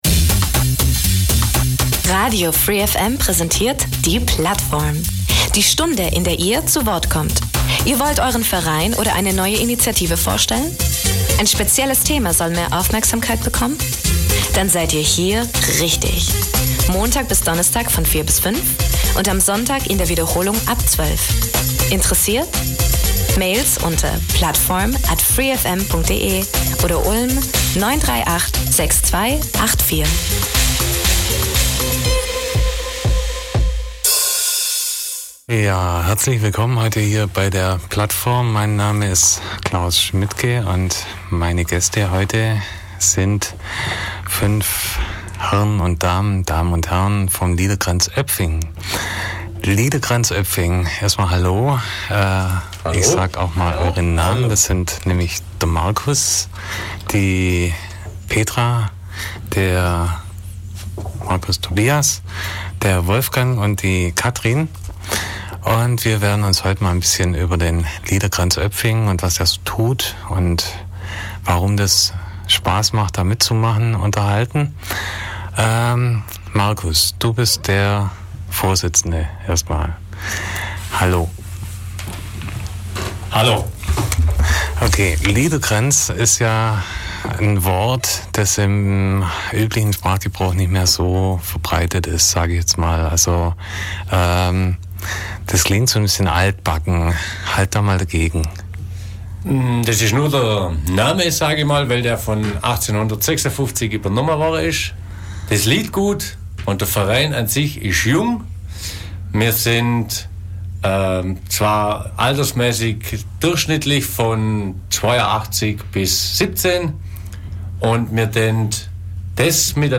Der Liederkranz Öpfingen ist ein gemischter Chor mit Mitgliedern zwischen 17 und 82 Jahren. Seit 1931 ist der Verein Mitglied im Schwäbischen Sängerbund.